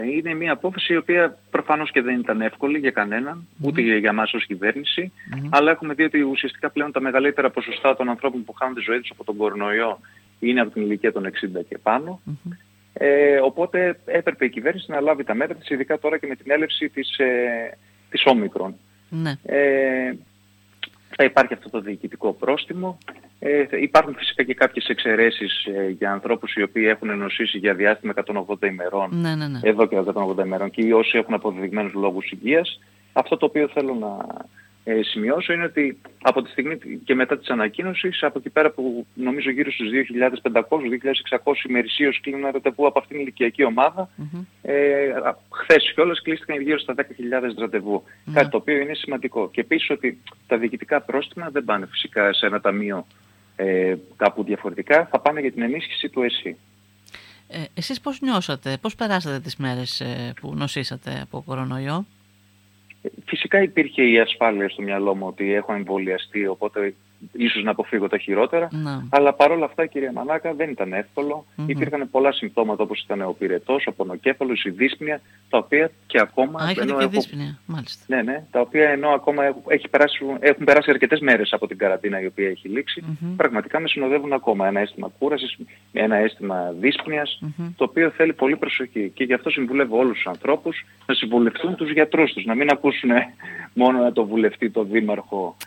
Την απόφαση της κυβέρνησης για τον υποχρεωτικό εμβολιασμό στους πολίτες άνω των 60 σχολίασε σήμερα μιλώντας στη ΕΡΤ Ορεστιάδας ο βουλευτής  Έβρου Χρήστος Δερμεντζόπουλος